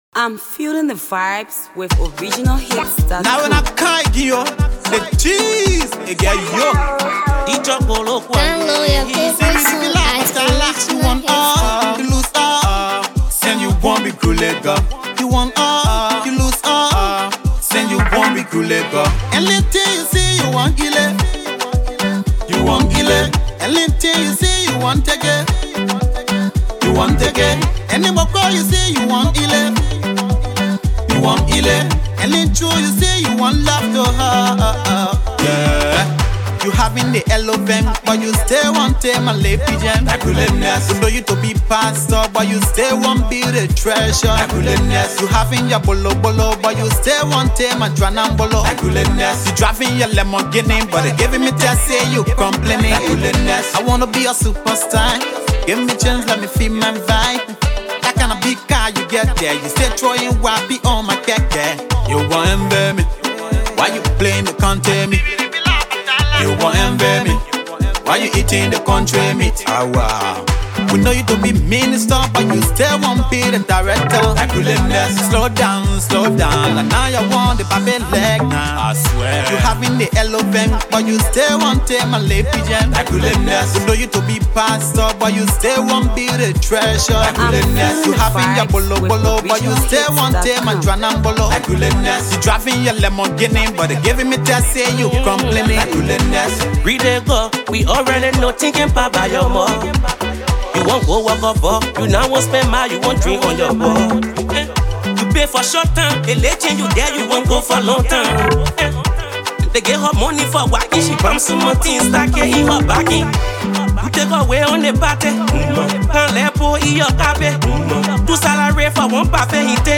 on this hot spite barz studio effort.